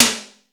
DANCE SD 2.wav